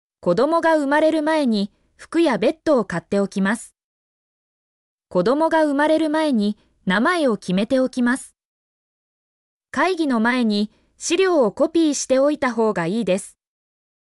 mp3-output-ttsfreedotcom-15_YpTKe3Uv.mp3